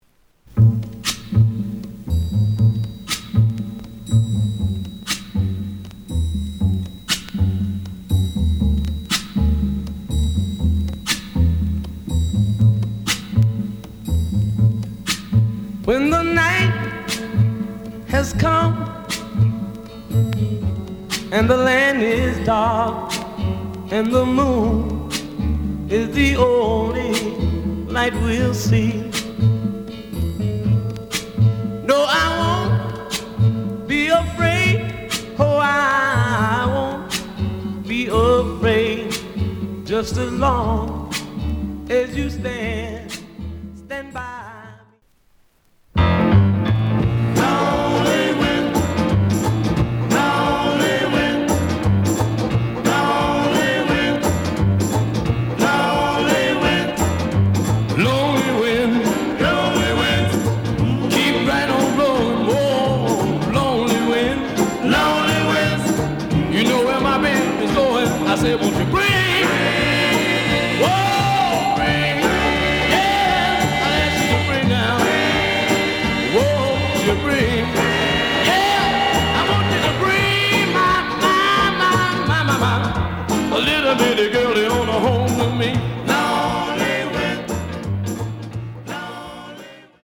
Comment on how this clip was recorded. The audio sample is recorded from the actual item. ●Format: LP